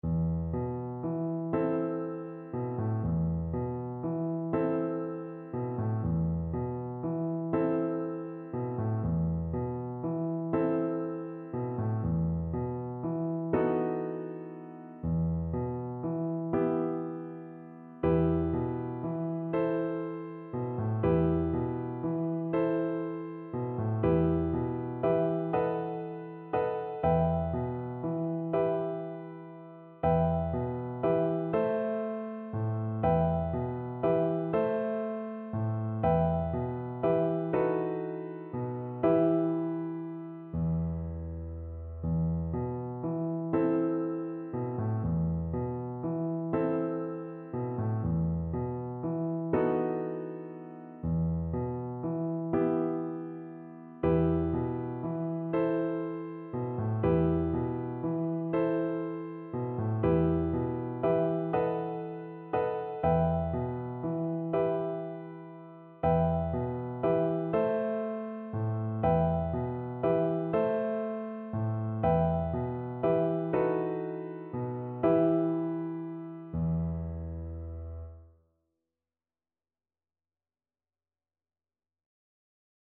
Play (or use space bar on your keyboard) Pause Music Playalong - Piano Accompaniment Playalong Band Accompaniment not yet available transpose reset tempo print settings full screen
E minor (Sounding Pitch) (View more E minor Music for Viola )
6/8 (View more 6/8 Music)
Gently rocking .=c.40